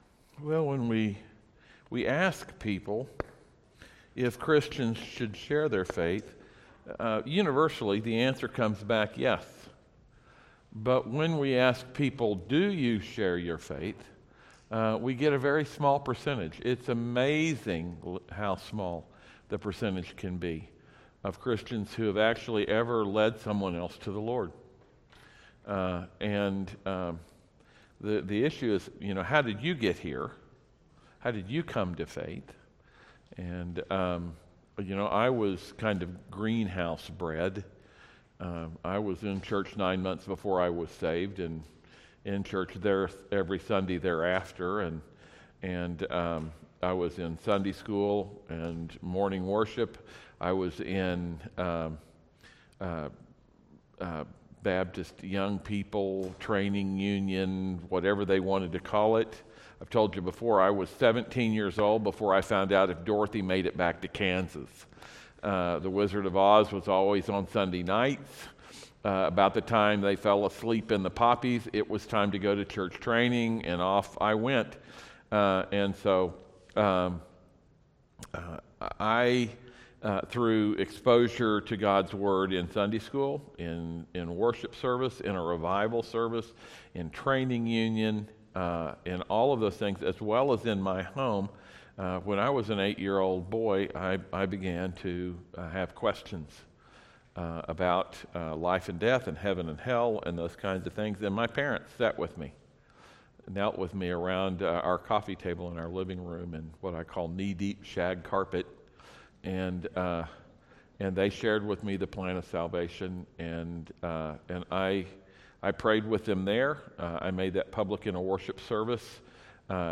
Sermon-Audio-for-October-8th-PM.mp3